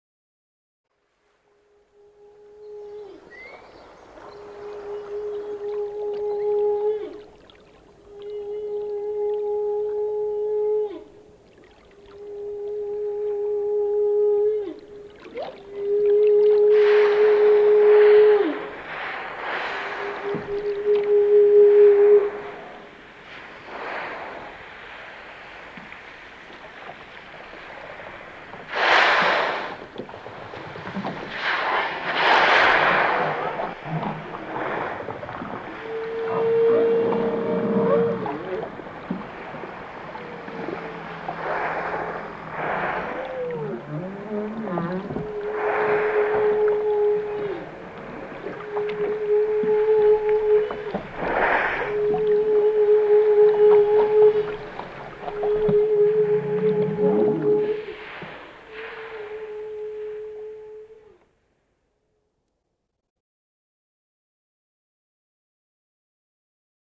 Canti e suoni delle balene.mp3